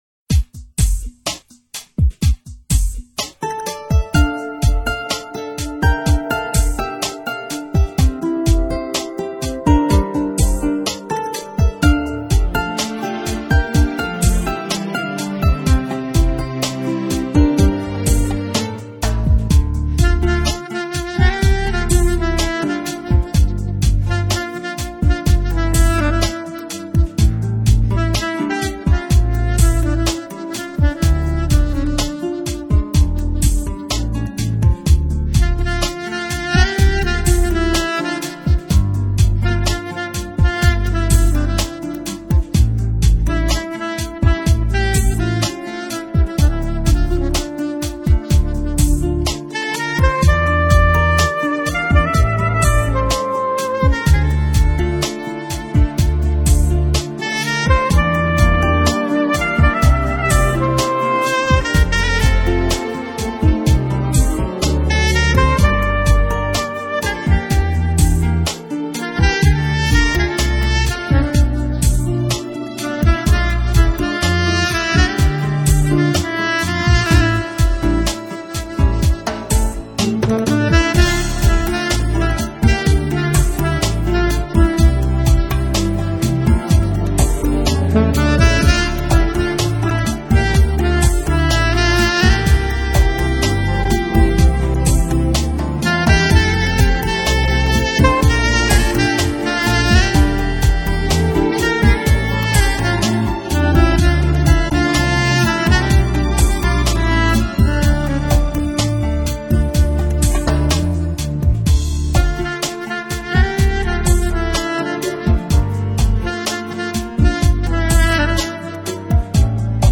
心，想要休息时，你需要与一支有灵魂的萨克斯风聊聊。